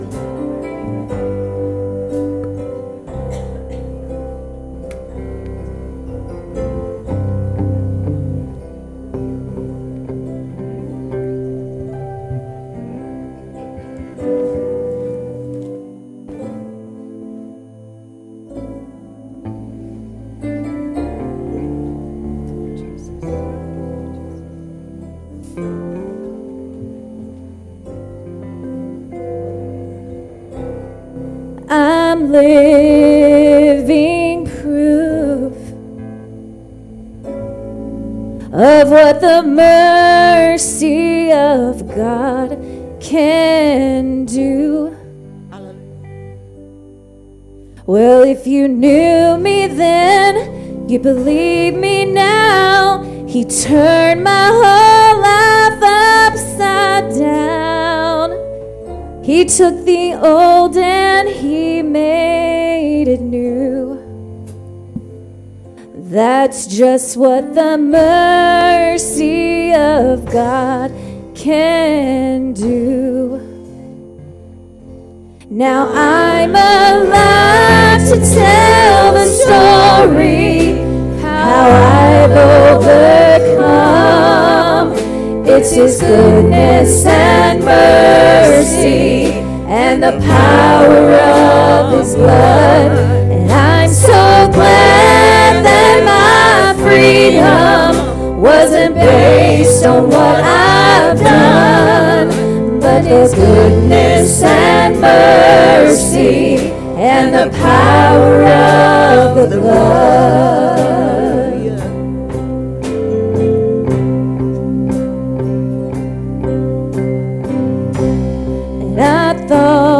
Singers/Testimony
Sunday Evening Services